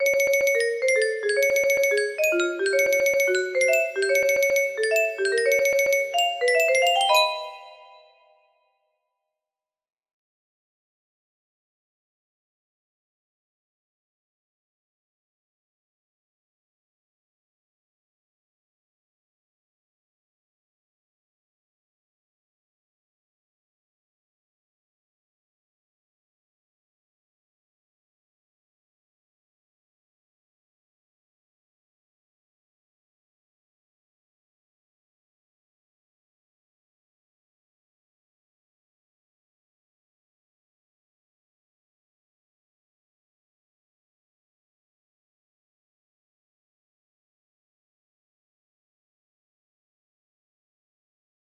hi music box melody